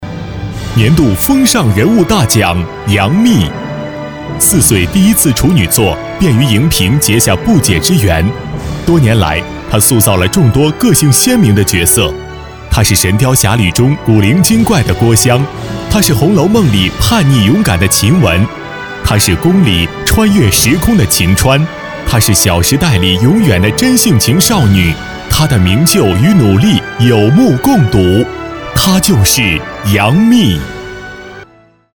晚会颁奖男152号（年度风
稳重男音，声线偏年轻。擅长多媒体解说，宣传片解说，mg动画，课件等题材。